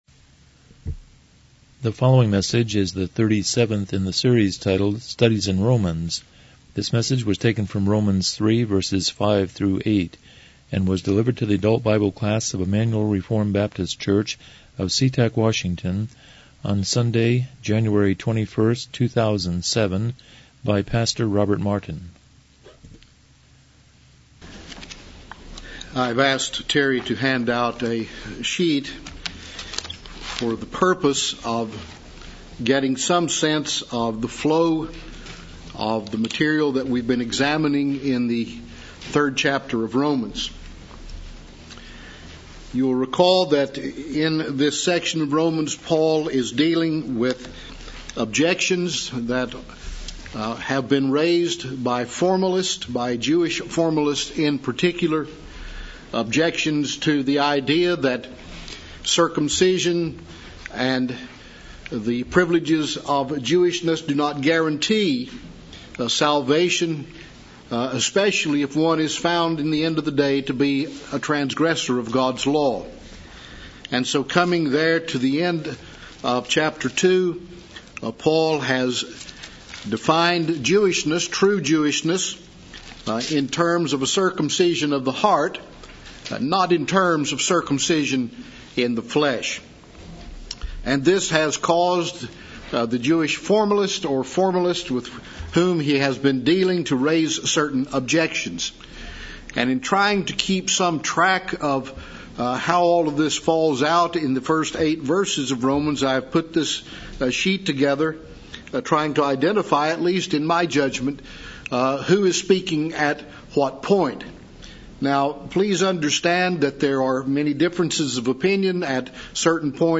Exposition of Romans Passage: Romans 3:5-8 Service Type: Sunday School « 36 Romans 3:3-4 8 Chapter 1.2 & 1.3 The Identity of Scripture